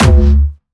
Jumpstyle Kick 9